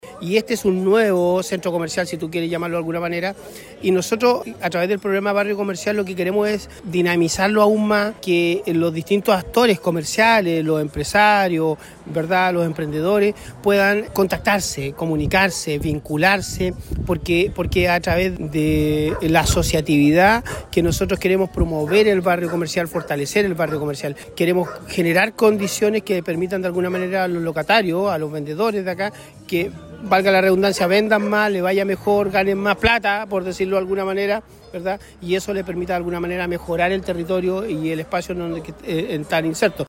En la Plaza Teniente Merino se llevó a cabo el lanzamiento oficial del Barrio Comercial Avenida Los Ríos de Laja, una nueva iniciativa impulsada por el Servicio de Cooperación Técnica (Sercotec) en el marco del programa Fortalecimiento de Barrios Comerciales, que busca potenciar el trabajo colaborativo entre emprendedores y comerciantes locales.
“Este es un sector que se ha ido desarrollando en la comuna de Laja, con un comercio pujante que queremos fortalecer aún más”, destacó Mauricio Torres Ferrada, director regional de Sercotec.